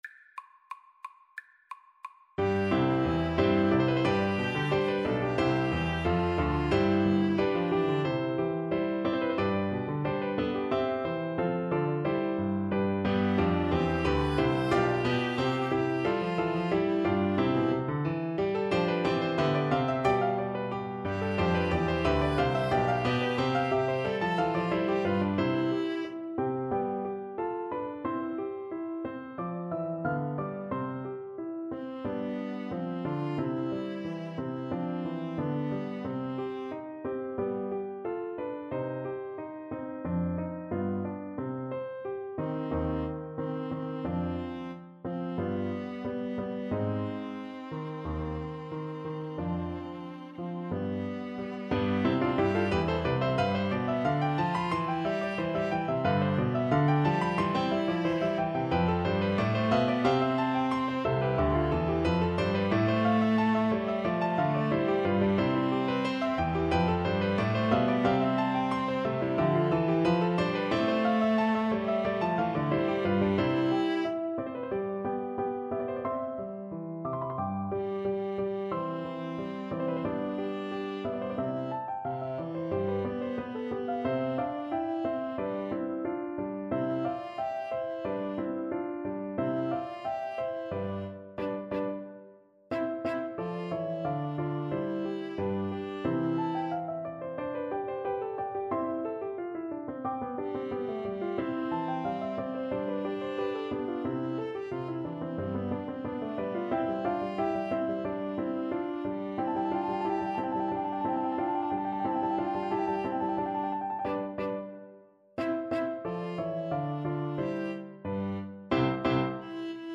ViolinViolaPiano
4/4 (View more 4/4 Music)
Allegro =180 (View more music marked Allegro)
Classical (View more Classical Piano Trio Music)